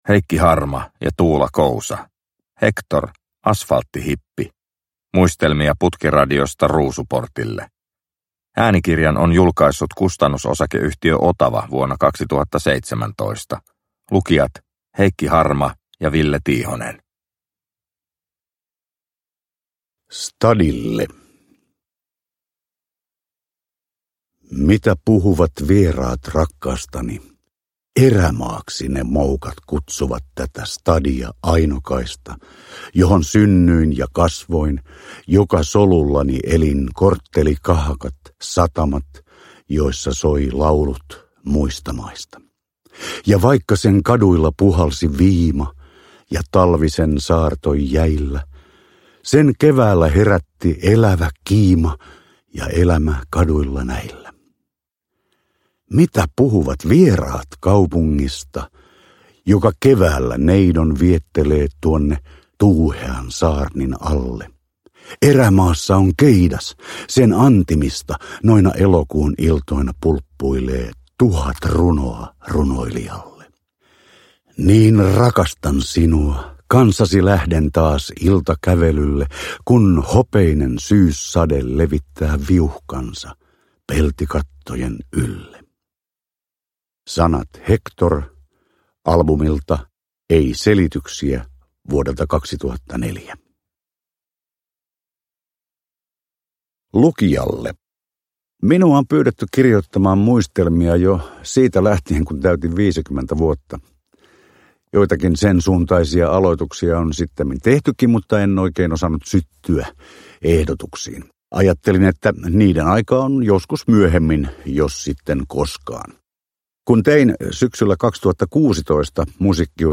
Hector - Asfalttihippi – Ljudbok – Laddas ner